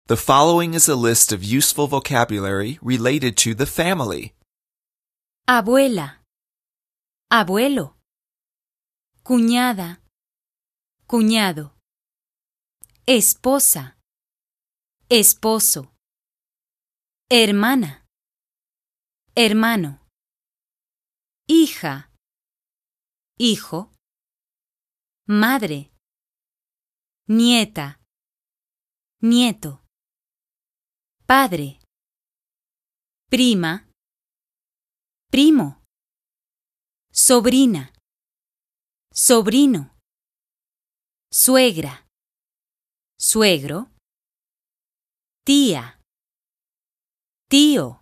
FS-tabla5.mp3